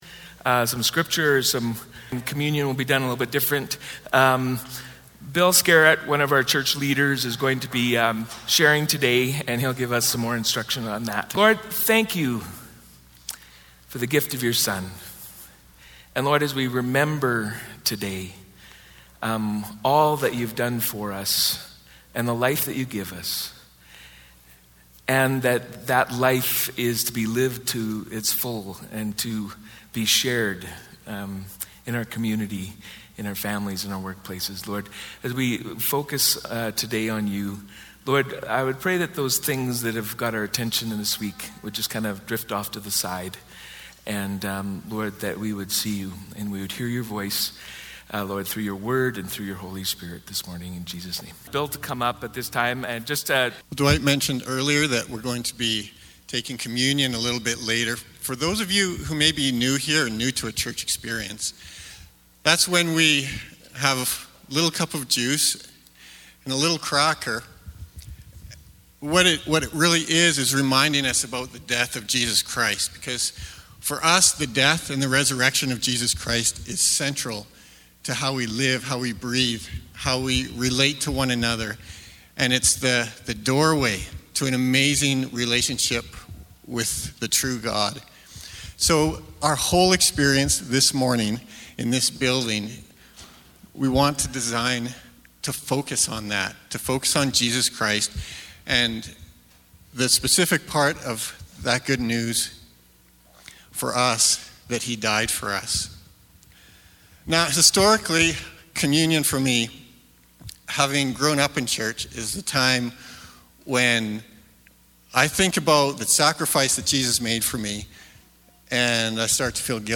This last weekend I had the privilege of being part of the team that led our church in remembering the death of Jesus. I was asked to share a short sermon, so I based it on the ways that I usually have to get my head right as I approach the cross of the Christ.